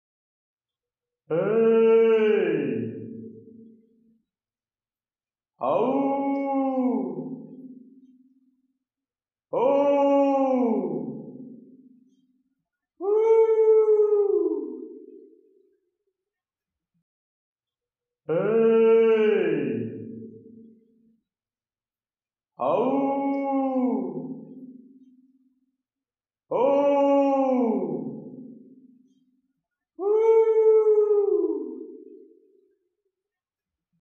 Звуки эха
Человек кричит ау в тоннеле, звуковые волны отражаются и создают эхо